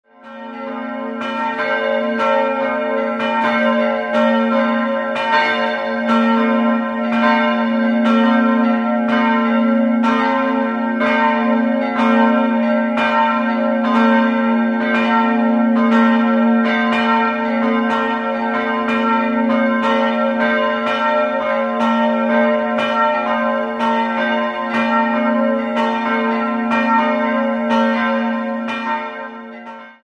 Aus den winzigen Schallöffnungen des Turmes erklingt ein sehr dezentes Dreiergeläute in ungewöhnlicher Tonfolge. 3-stimmiges Geläute: b'-c''-f'' Nähere Daten liegen nicht vor.